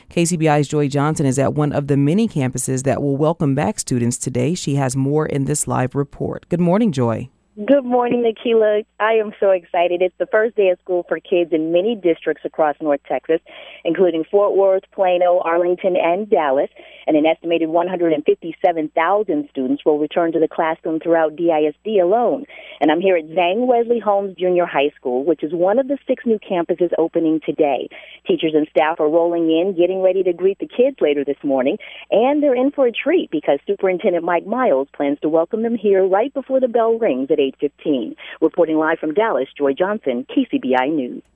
Students will return back to school today after a long summer! New schools opened in the Dallas Independent School District (DISD) and new Superintendent Mike Miles prepares to greet students on their first day school. Live from Grand Prairie 7:00 am.